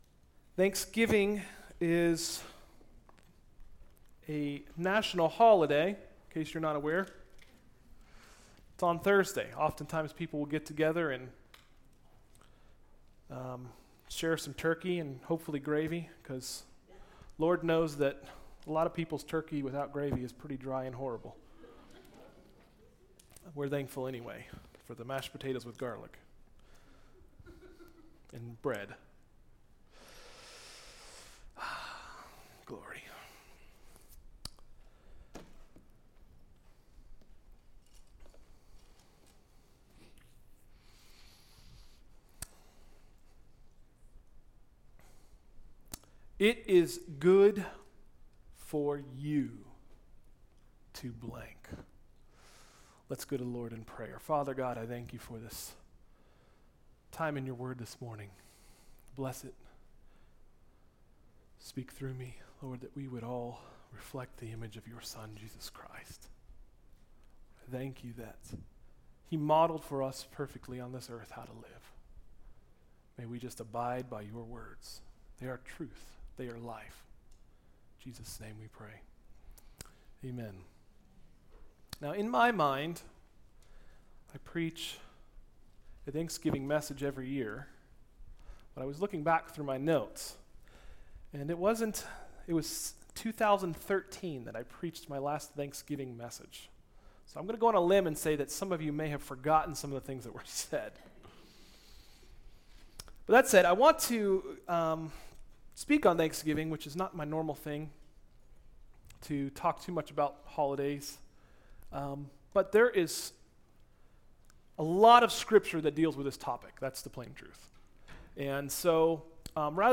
Sermons: “It is Good to _______” – Tried Stone Christian Center